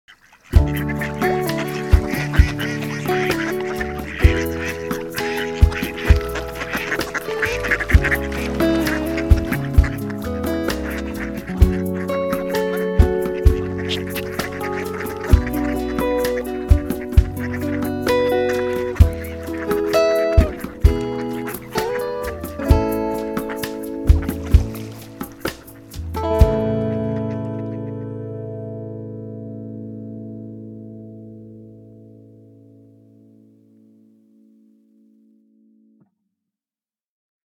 Звуки и голос уток
Дикие прибрежные утки играют на природе